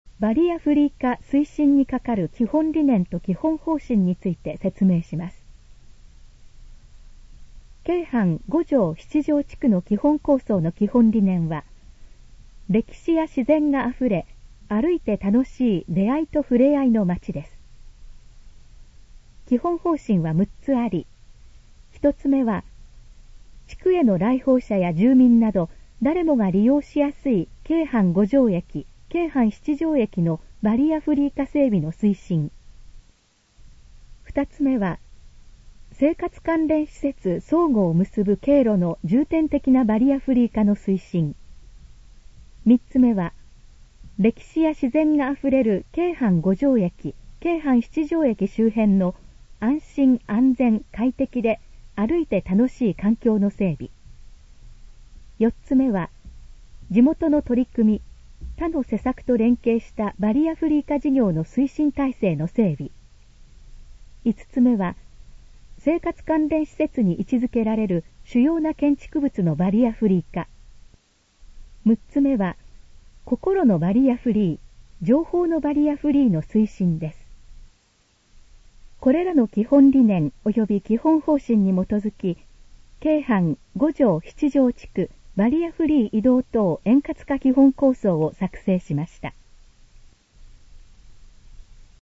このページの要約を音声で読み上げます。
ナレーション再生 約360KB